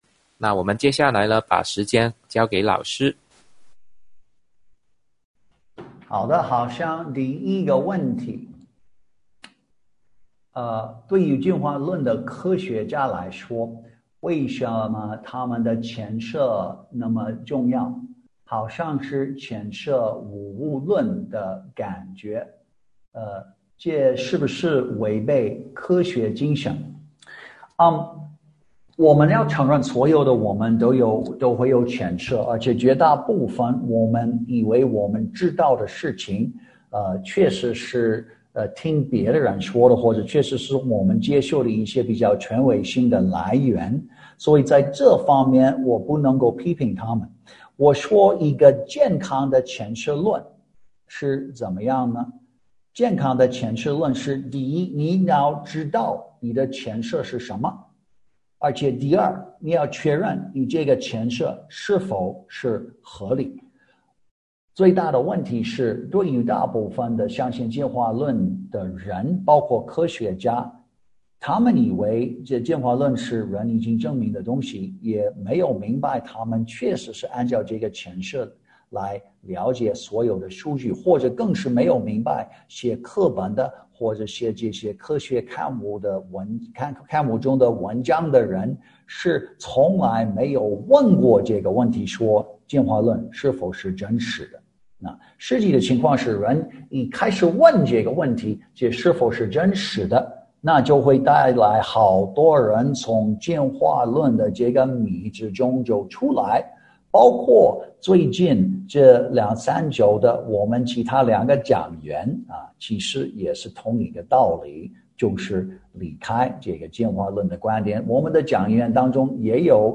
《科学家为什么相信进化论和年老地球》 讲座直播回放